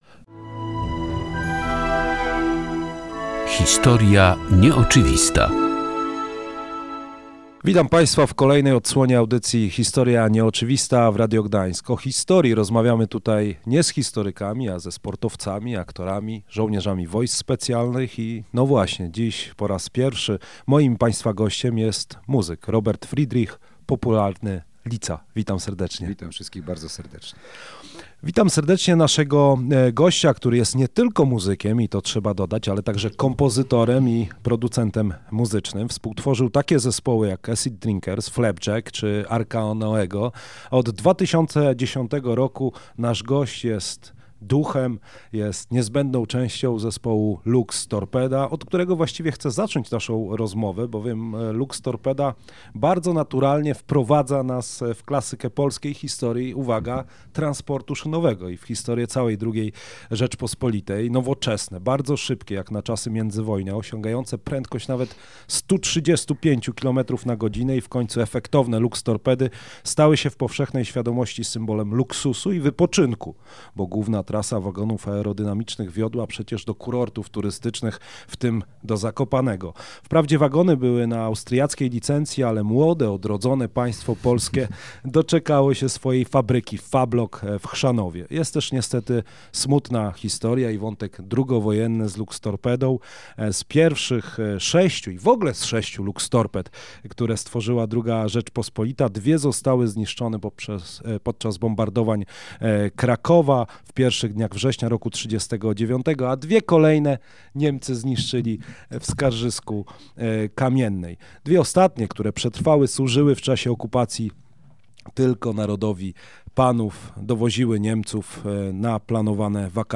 Thrash metal szybki jak… Luxtorpeda. Rozmowa z Robertem „Litzą” Friedrichem [HISTORIA NIEOCZYWISTA]
Z muzykiem rozmawiał dr Karol Nawrocki, dyrektor Muzeum II Wojny Światowej.